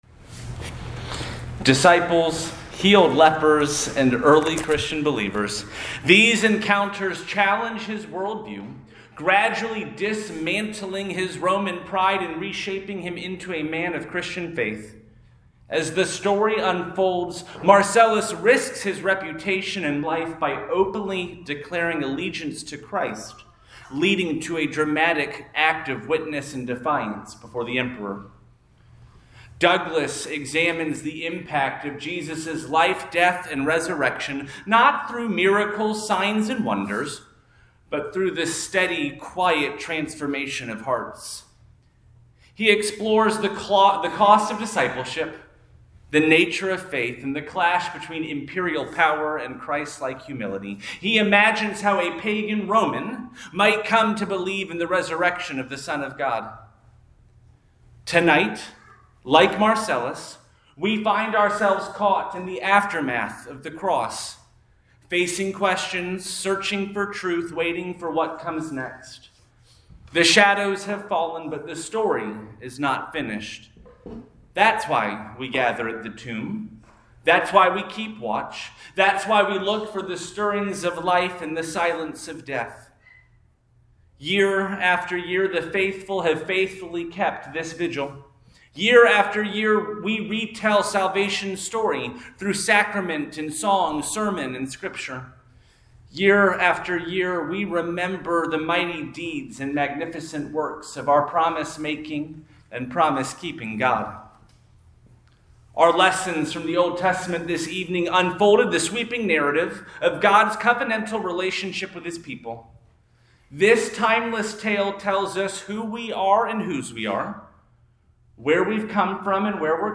Easter Vigil